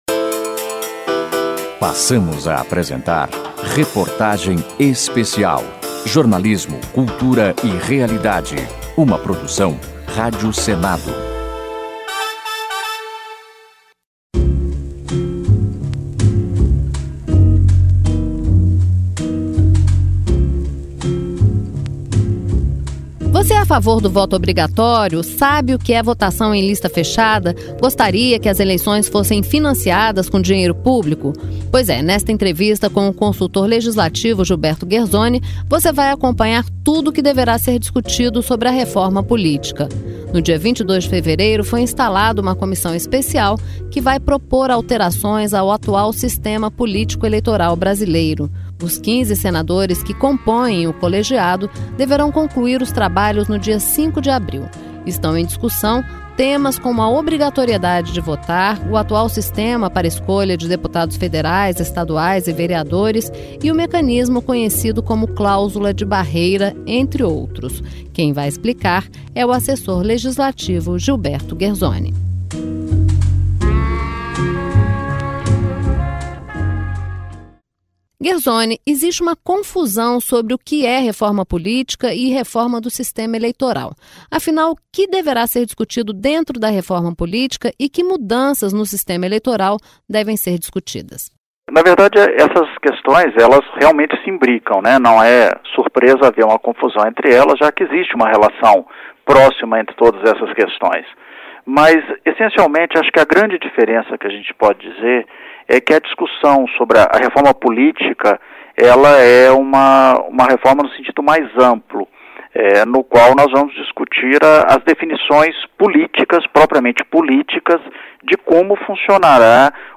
Reportagens abordando temas de interesse da sociedade. Cultura, história do Brasil e direitos da cidadania.